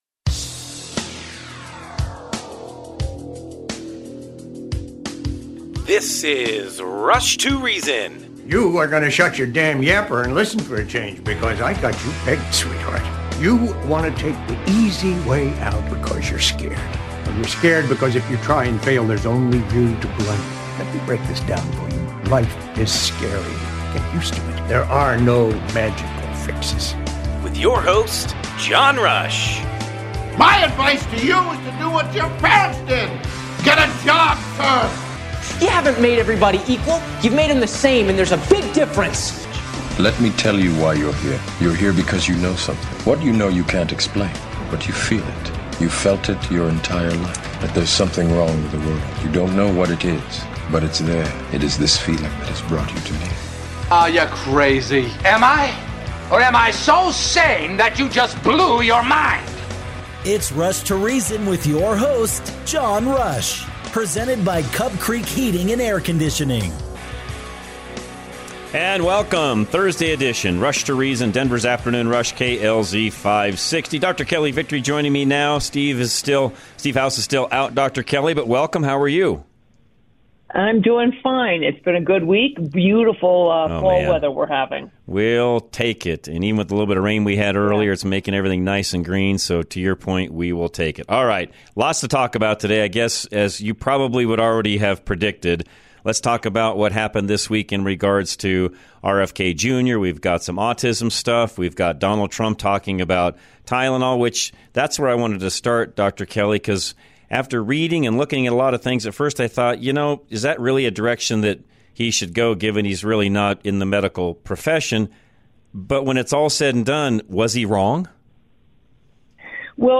Interviews 84 Shots Before Adulthood.